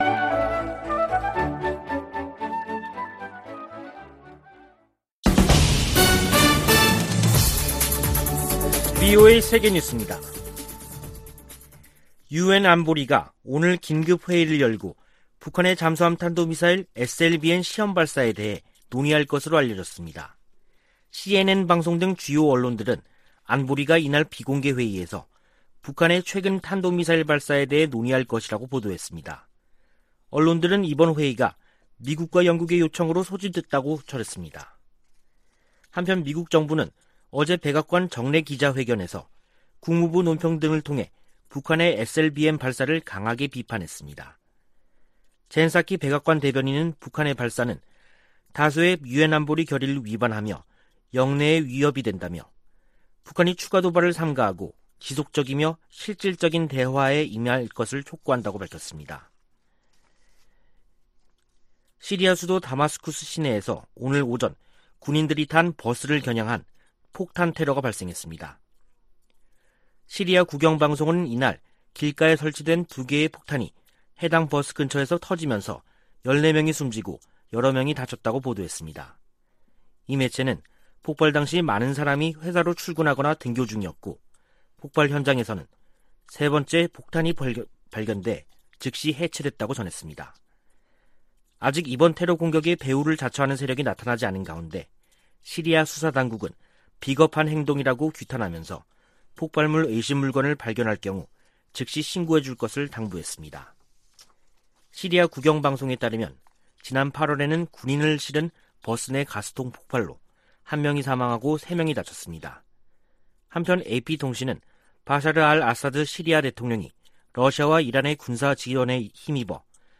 VOA 한국어 간판 뉴스 프로그램 '뉴스 투데이', 2021년 10월 20일 3부 방송입니다. 미국 정부는 북한의 탄도미사일 발사에 우려하며 조속히 대화에 나설 것을 촉구했습니다. 유엔은 북한 미사일과 관련, 국제 의무를 준수하고 외교노력을 재개하라고 촉구했습니다. 최근 미국과 한국, 일본 고위 당국자들의 잇따른 회동에 관해 미국의 전문가들은 불투명한 한반도 상황 속에서 협력을 강화하고 견해 차를 좁히려는 움직임으로 분석했습니다.